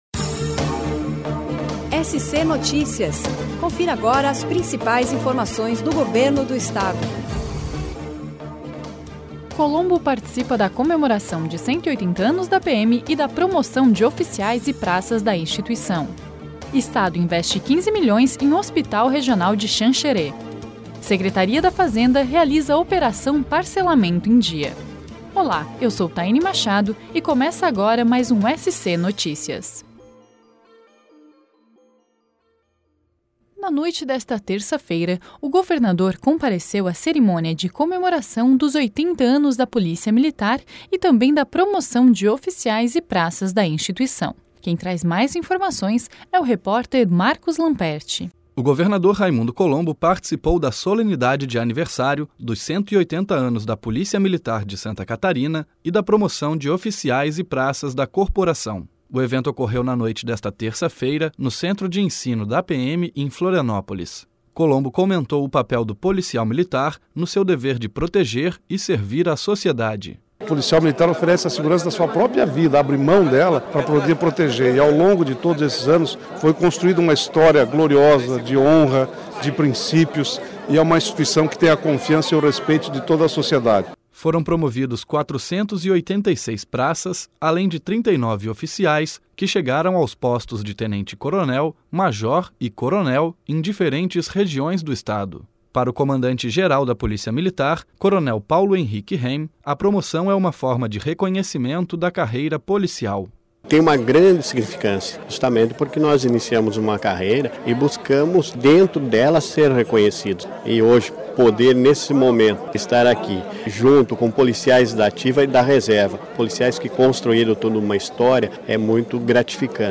O SC Notícias é um programa produzido diariamente pelo Serviço de Rádio da Secretaria de Estado da Comunicação com as principais informações do Governo do Estado.